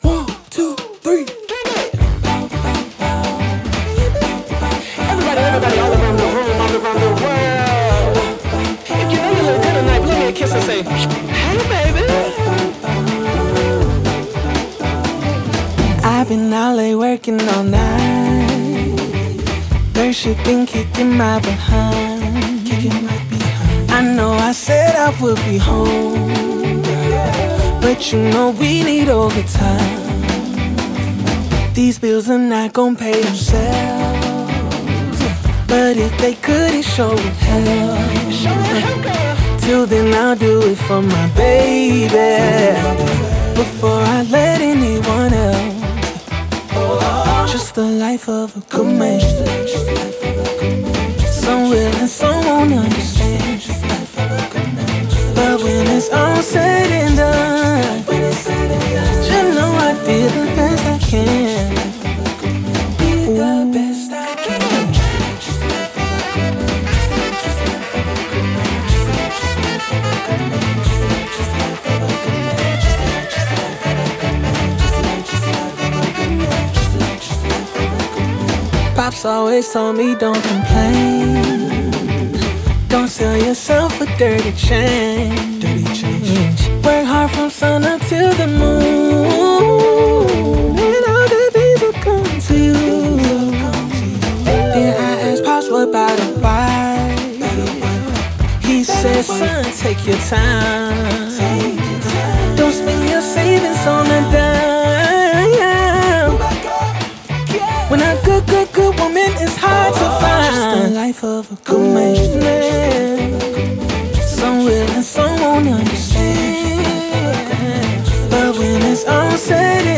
Jazz
G Major